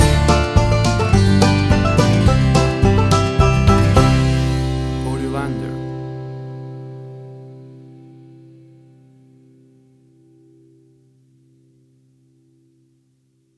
Warm and earth country music.
Tempo (BPM): 106